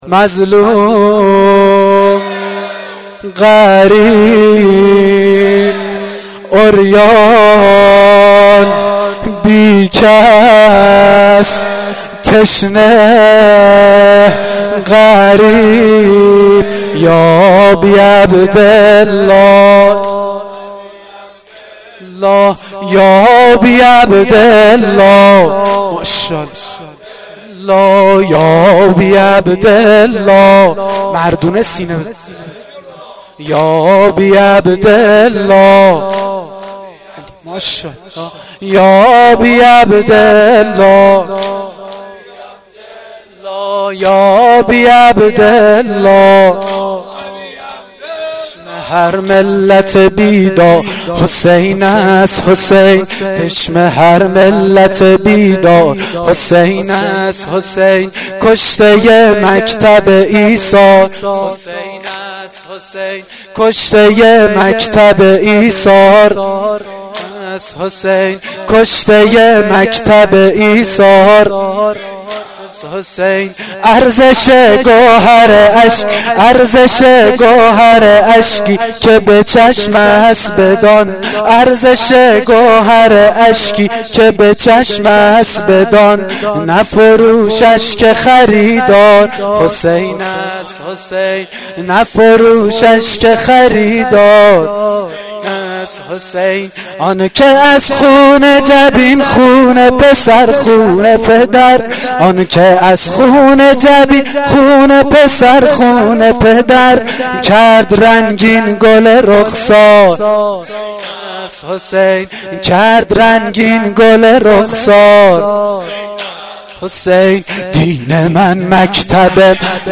واحد شام غریبان محرم1393